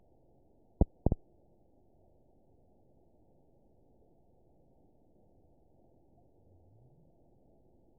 event 922736 date 03/24/25 time 02:48:23 GMT (1 month, 1 week ago) score 9.46 location TSS-AB01 detected by nrw target species NRW annotations +NRW Spectrogram: Frequency (kHz) vs. Time (s) audio not available .wav